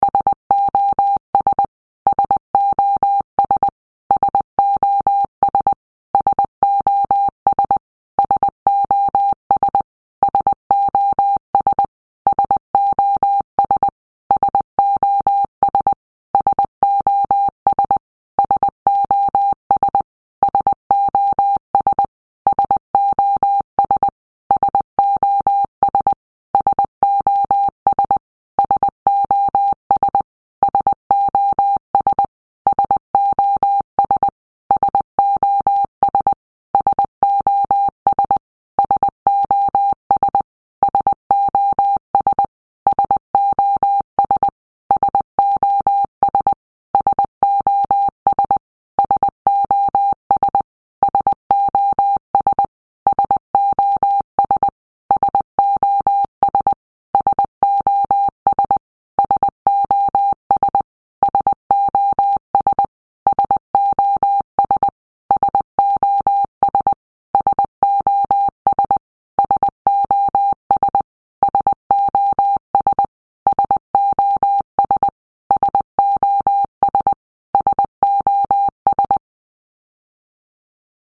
SOS sound in Morse Code (Medium)